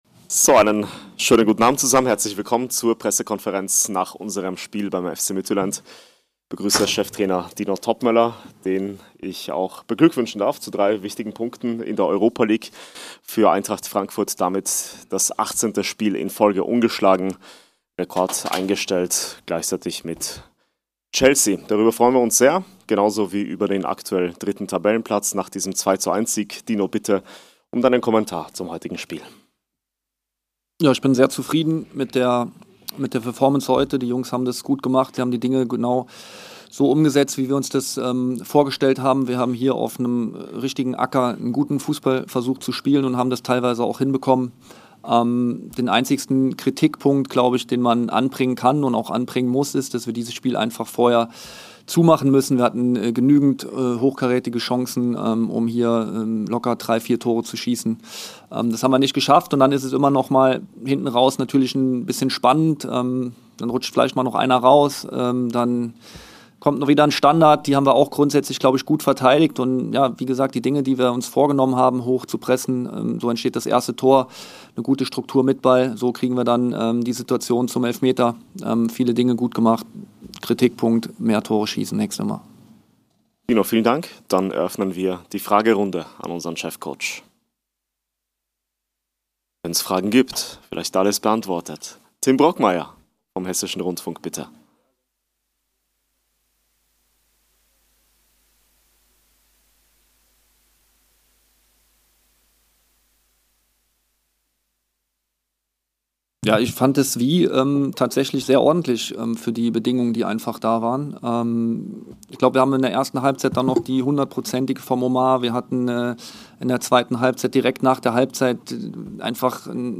Die Pressekonferenz mit unserem Cheftrainer Dino Toppmöller nach dem Europa-League-Spiel gegen den dänischen Meister.